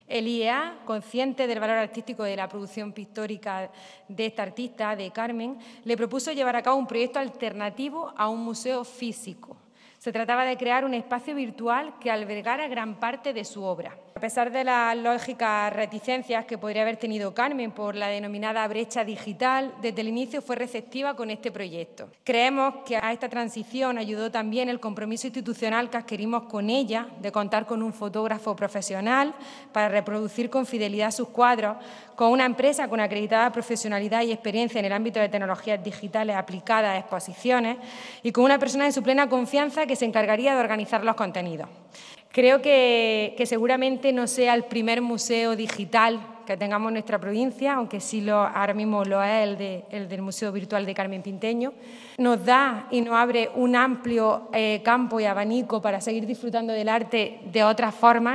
El Patio de Luces ha albergado la presentación de esta iniciativa del Instituto de Estudios Almerienses sobre la pintora más premiada de la historia de Almería y más reconocida por las instituciones
29-11_museo_virtual_diputada.mp3